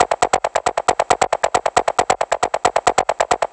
• techno arp sequence shaker like 136.wav
techno_arp_sequence_shaker_like_136_DXJ.wav